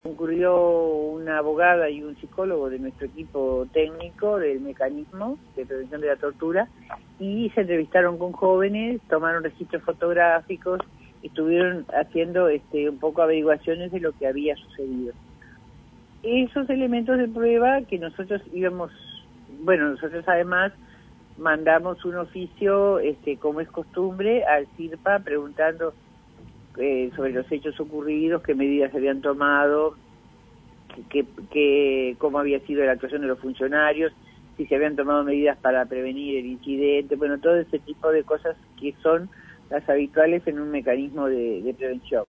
Entrevistada por Rompkbzas, Guianze dijo que habían sido notificados de estos hechos por los propios familiares de los jóvenes y que les habían llegado varias denuncias de distintas organizaciones por lo que decidieron concurrir al lugar.